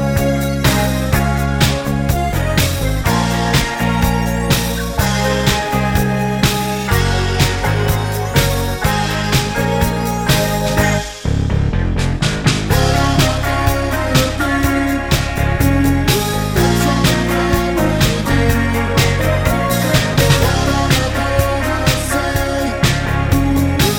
Down 3 Semitones Pop (1980s) 3:40 Buy £1.50